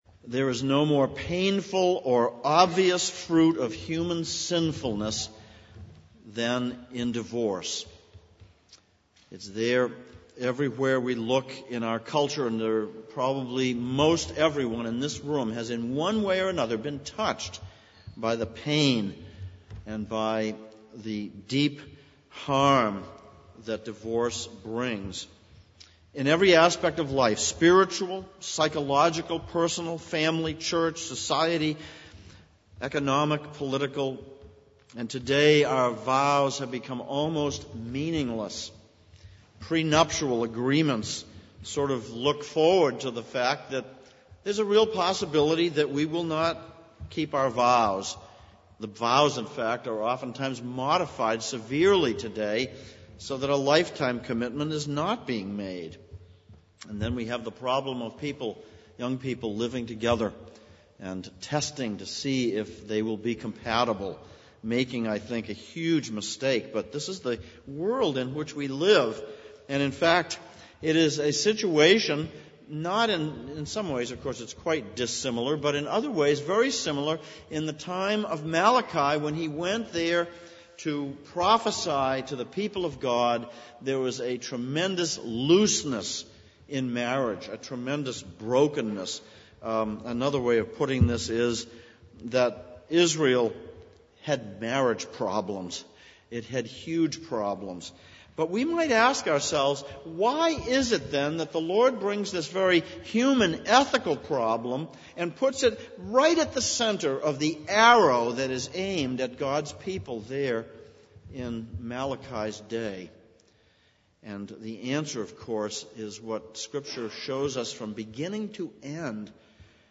Passage: Malachi 2:10-16, 1 Corinthians 7:1-16 Service Type: Sunday Morning